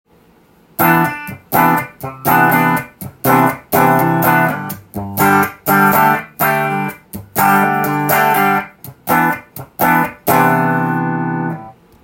ピックアップマイクにセイモアダンカンを搭載し
試しに弾いてみました
ハムバッカーのクリーントーンが良い感じです。